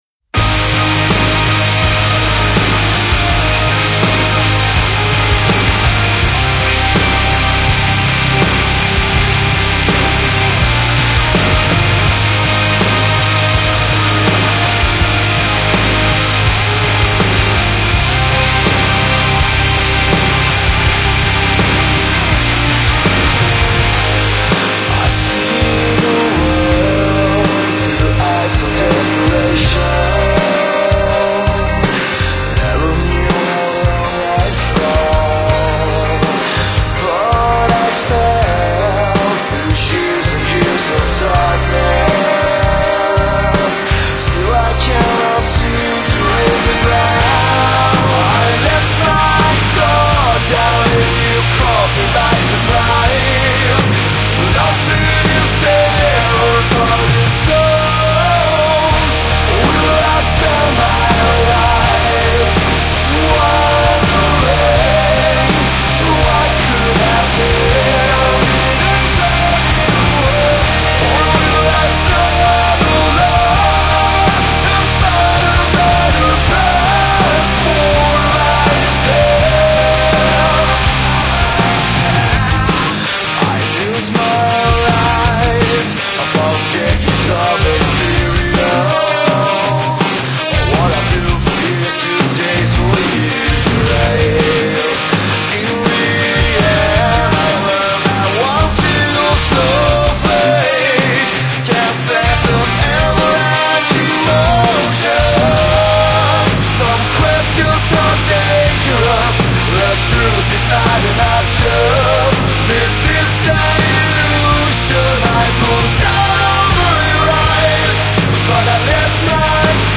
ппц как задушевно